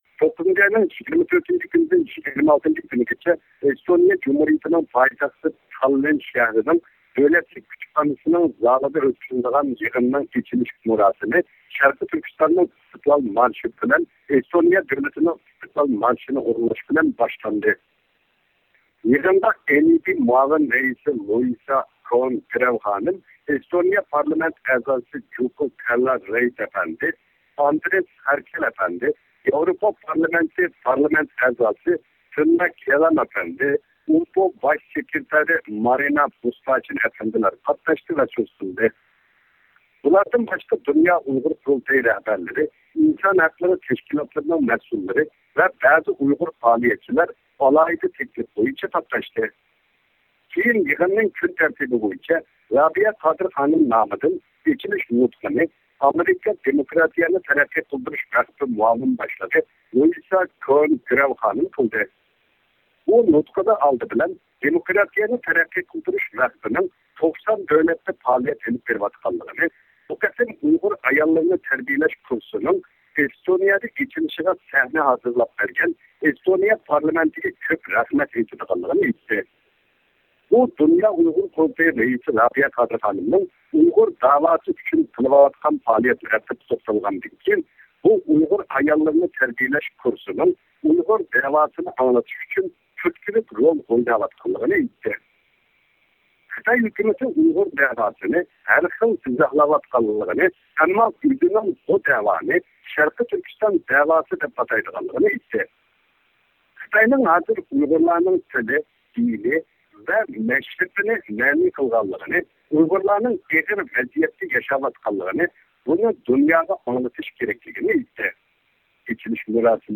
ئىختىيارىي مۇخبىرىمىز